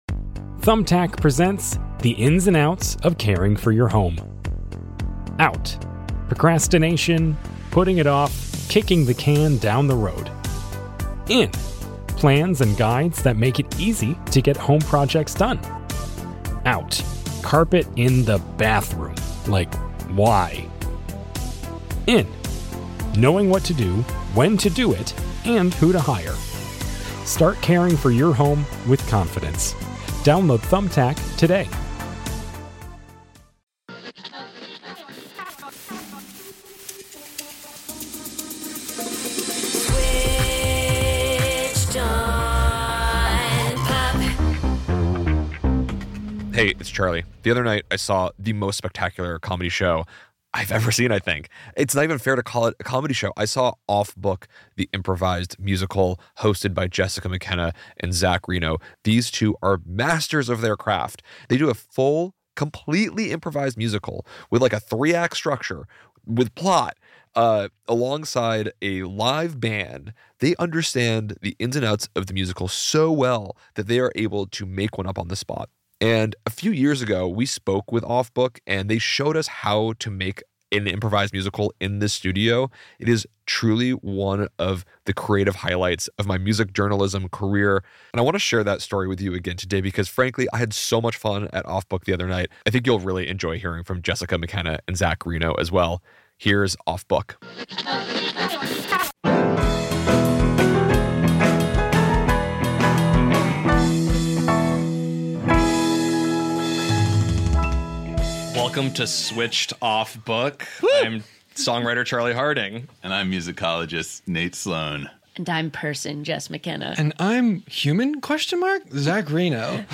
Stay tuned for deep thoughts about what separates pop music from musicals, wild speculation about the origin of the word “vamp,” and an ENTIRE FREAKING MUSICAL COMPOSED FROM SCRATCH that will make you laugh your face off.